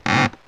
Index of /90_sSampleCDs/E-MU Producer Series Vol. 3 – Hollywood Sound Effects/Human & Animal/WoodscrewSqueaks
WOOD SQUEA07.wav